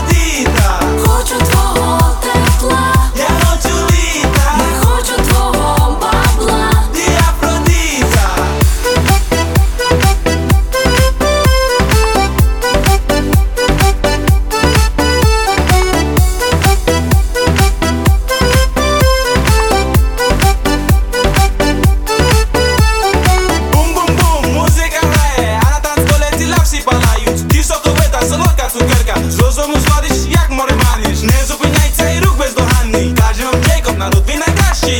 Жанр: Танцевальные / Украинские